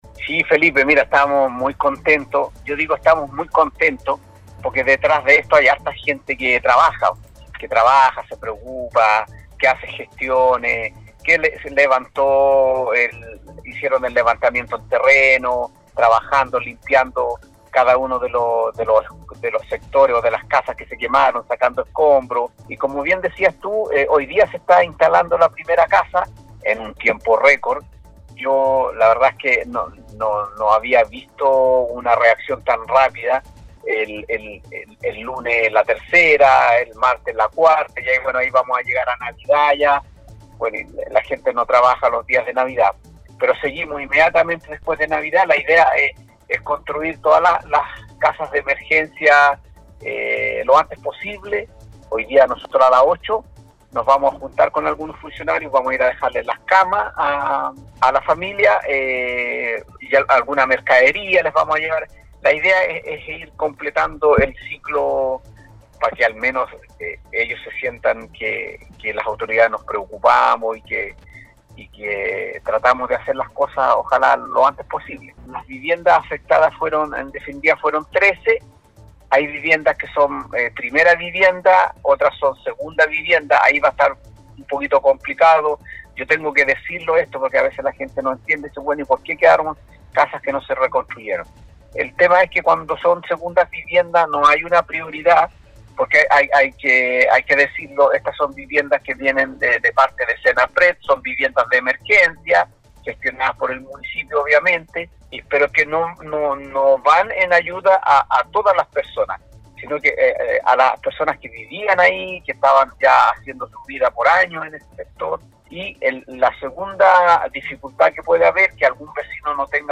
Declaraciones completas de Manuel Devia, Alcalde de San Pedro.